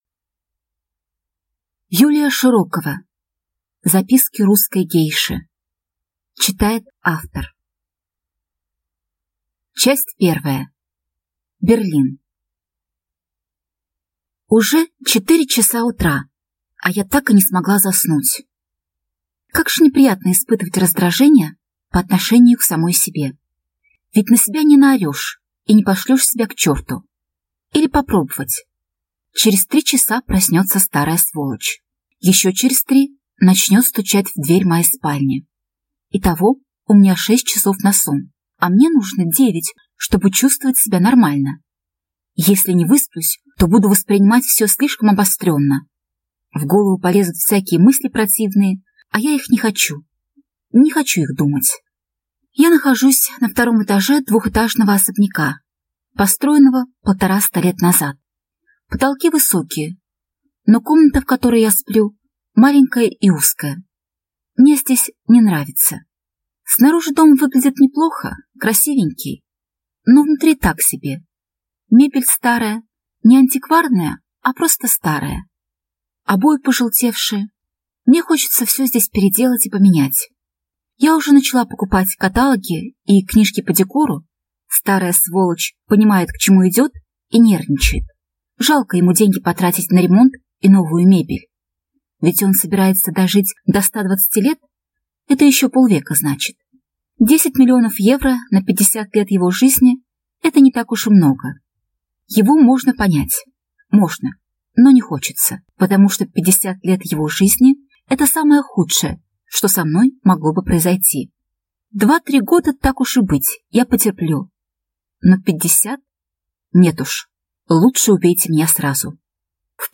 Аудиокнига Записки русской гейши | Библиотека аудиокниг